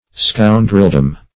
Search Result for " scoundreldom" : The Collaborative International Dictionary of English v.0.48: Scoundreldom \Scoun"drel*dom\, n. The domain or sphere of scoundrels; scoundrels, collectively; the state, ideas, or practices of scoundrels.
scoundreldom.mp3